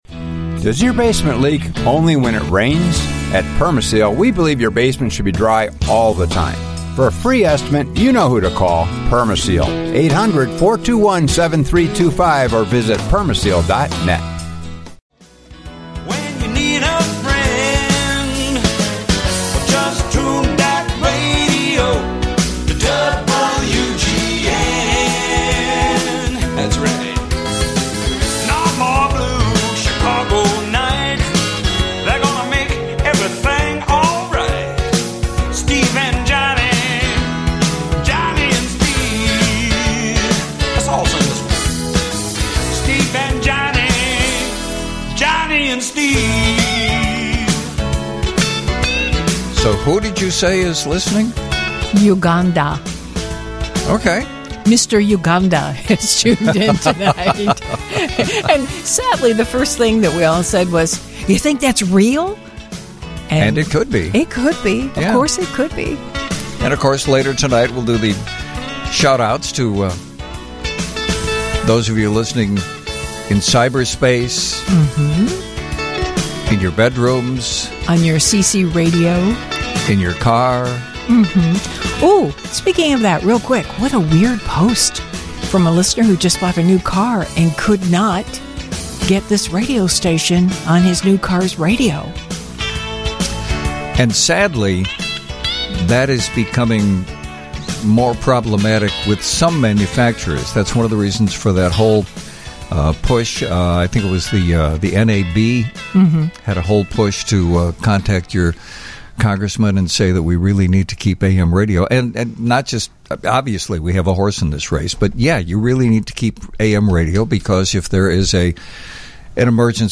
Along the way, we have a cavalcade of classic characters and a medley of music sure to keep you entertained!